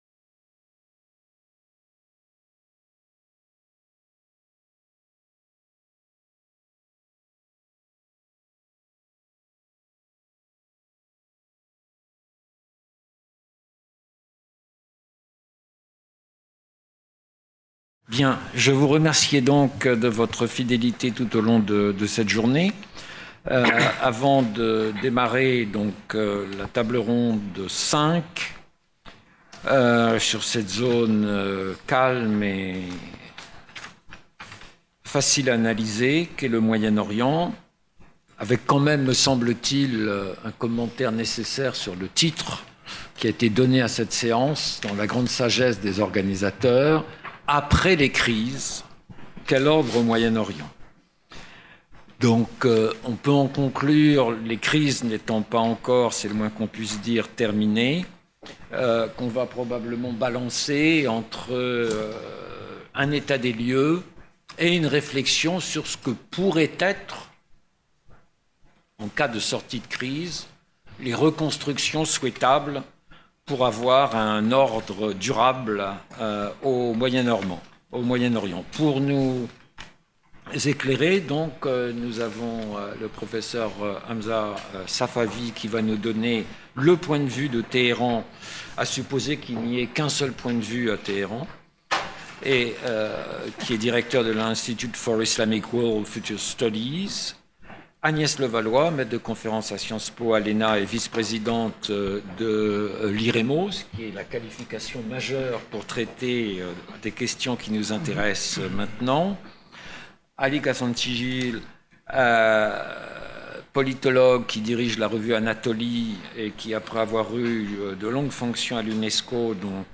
Table ronde 5 – Après les crises, quel ordre au Moyen Orient ?